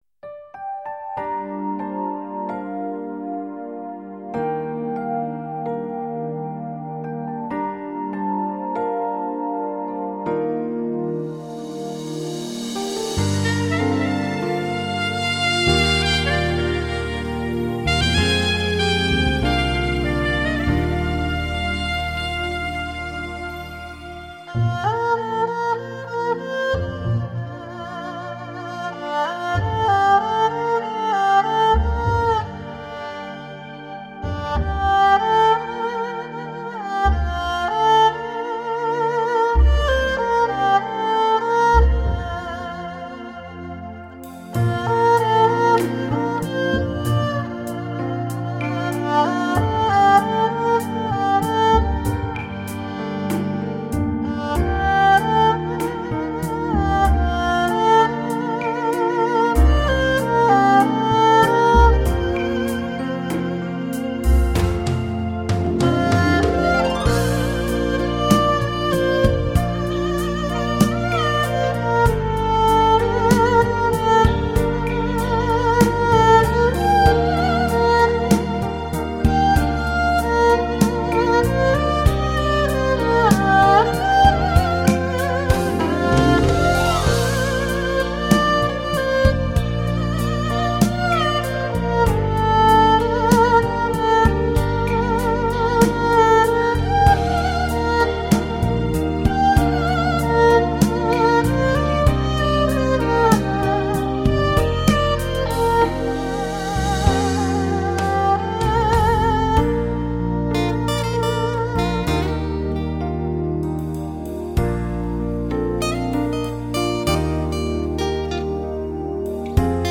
二胡演奏